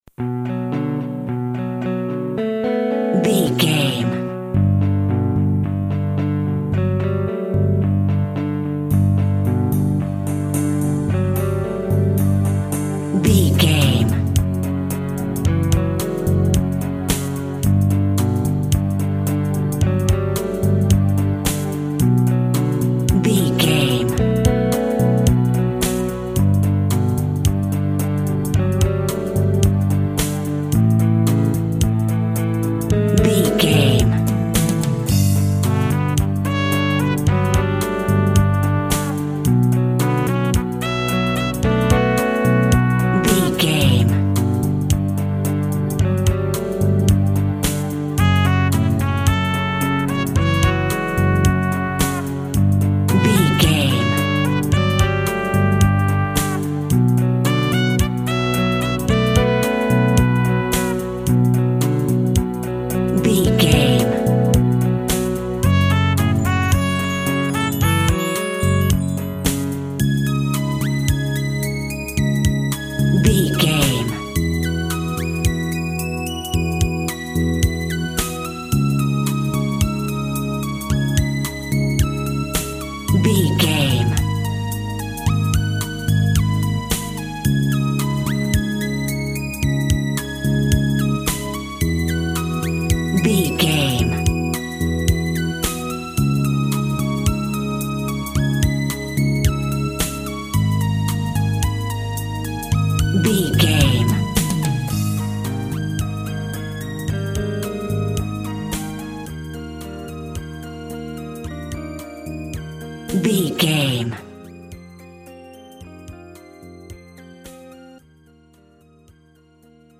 Eighties Smooth Rock.
Aeolian/Minor
melancholy
dreamy
tranquil
groovy
drums
electric guitar
brass
synthesiser
synth bass